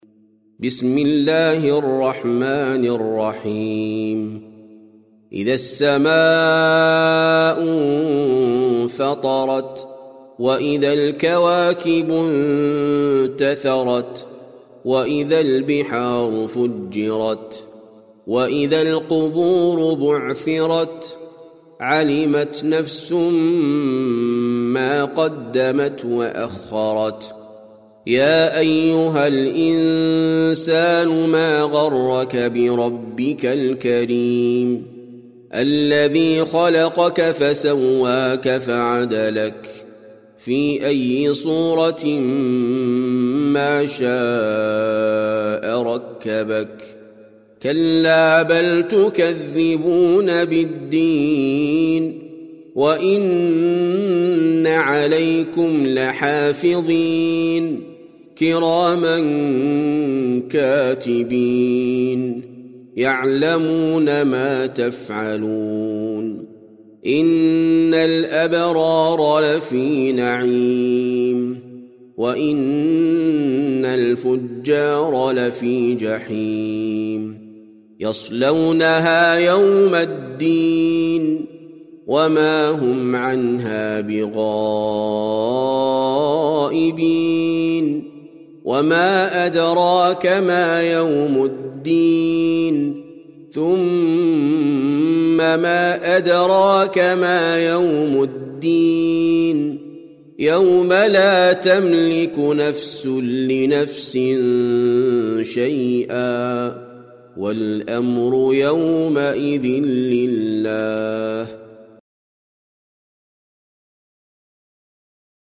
سورة الانفطار - المصحف المرتل (برواية حفص عن عاصم)
جودة عالية